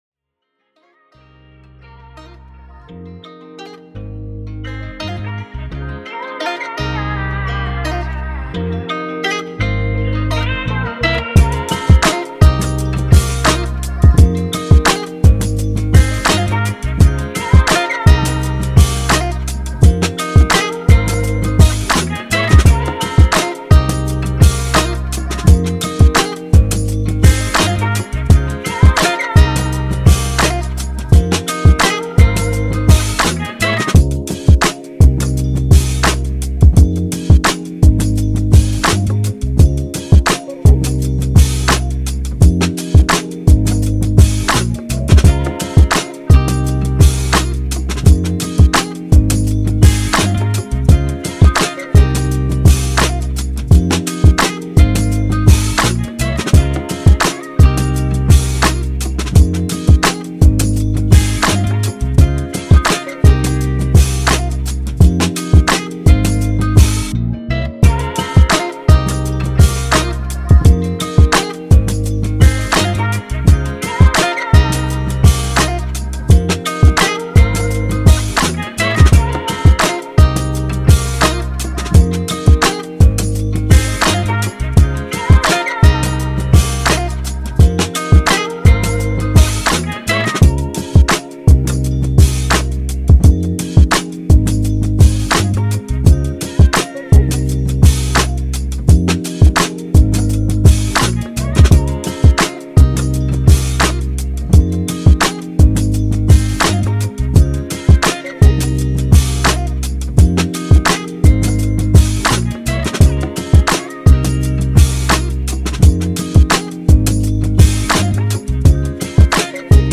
00s, Hip Hop, R&B
Fm
Hip-Hop/R&B style love joint